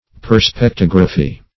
Search Result for " perspectography" : The Collaborative International Dictionary of English v.0.48: Perspectography \Per`spec*tog"ra*phy\ (p[~e]r`sp[e^]k*t[o^]g"r[.a]*f[y^]), n. The science or art of delineating objects according to the laws of perspective; the theory of perspective.